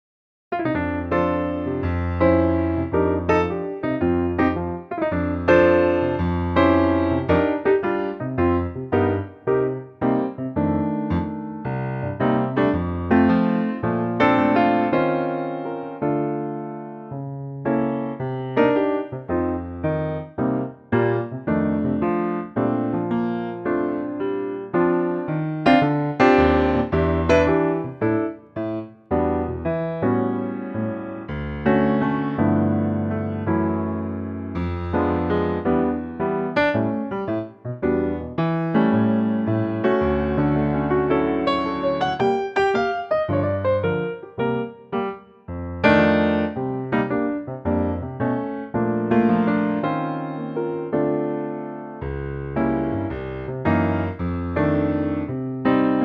Unique Backing Tracks
key - Bb - vocal range - F to G